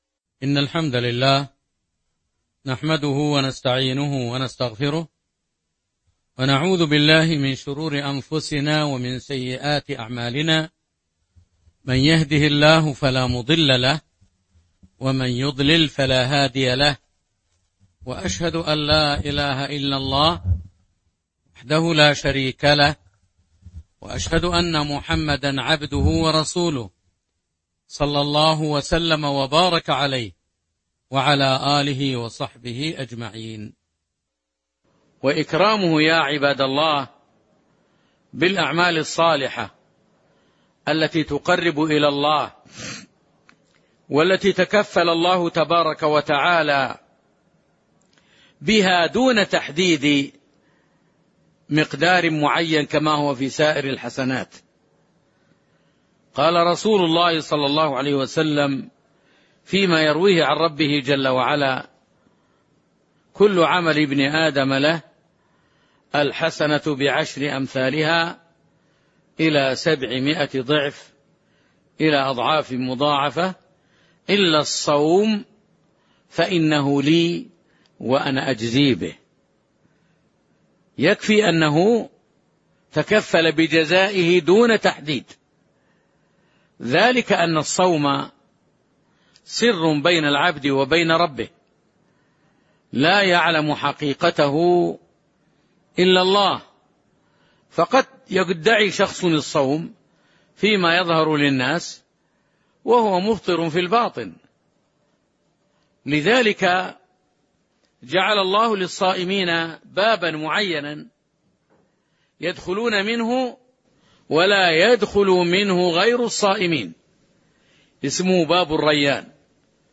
تاريخ النشر ٨ رمضان ١٤٤٢ هـ المكان: المسجد النبوي الشيخ